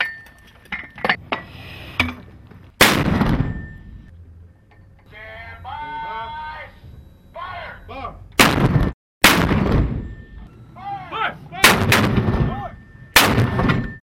Heavy Mortar Artillery System and "FIRE!" voice commands
Category 🗣 Voices
arming Artillery attack bam bang battle bombardment boom sound effect free sound royalty free Voices